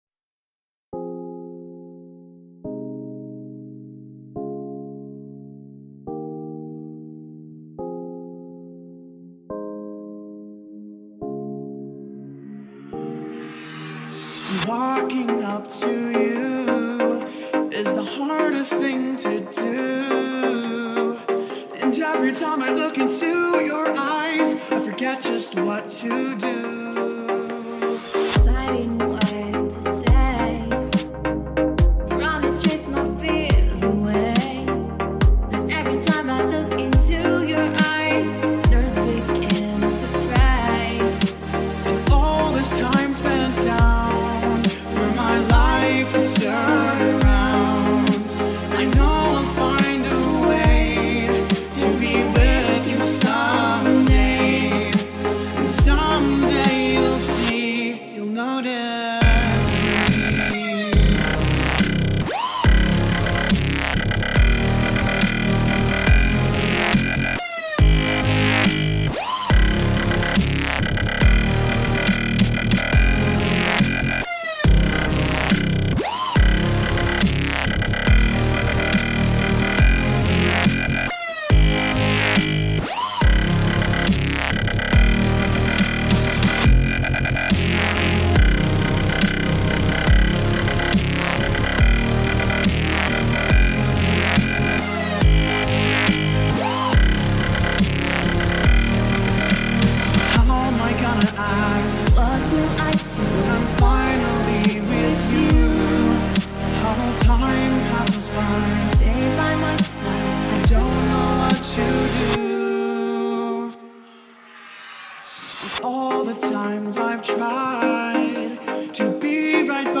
genre:dubstep
genre:remix